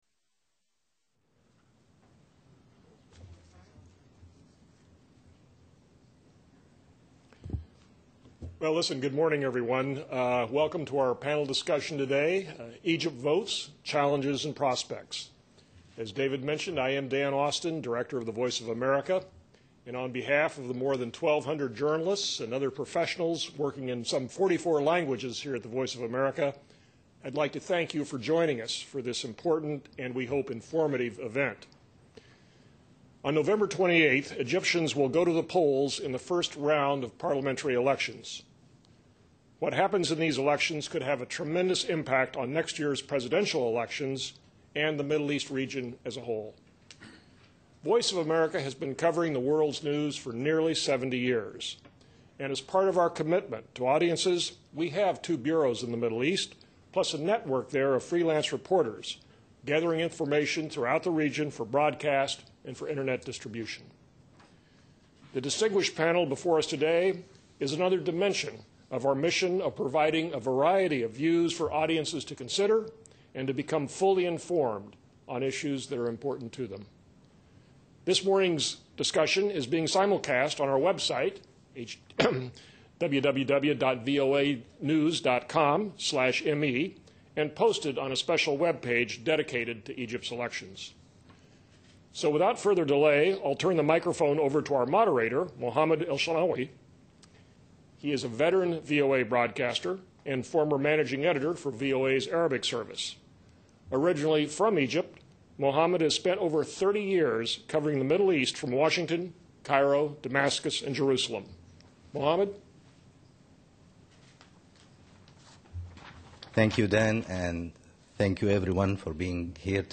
VOA recently hosted a panel discussion on the election.